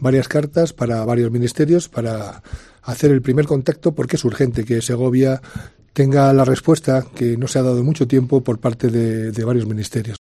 José Mazarías, alcalde de Segovia, sobre la reclamación a ministerios de temas pendientes